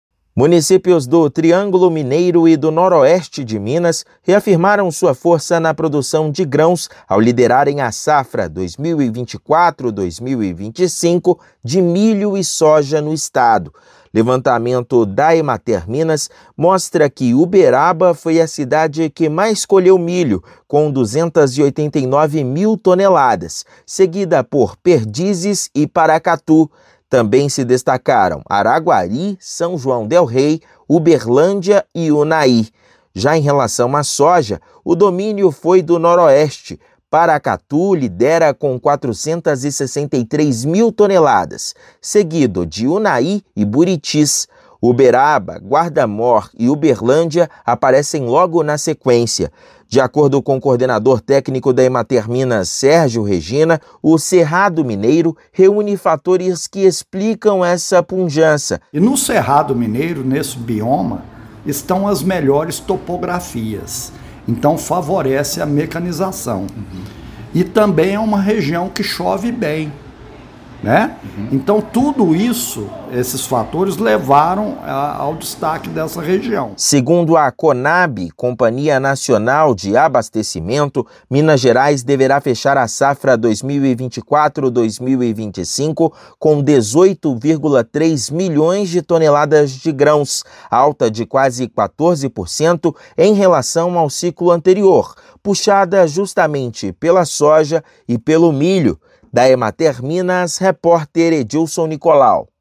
Levantamento da Emater mostra que Uberaba foi a cidade que mais colheu milho, seguida por Perdizes e Paracatú. Ouça matéria de rádio.